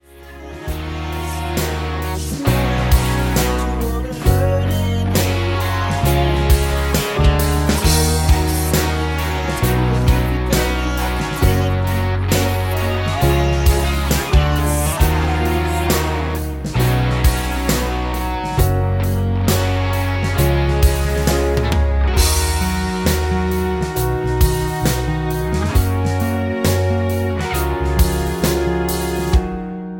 D#m
MPEG 1 Layer 3 (Stereo)
Backing track Karaoke
Pop, Country, 2000s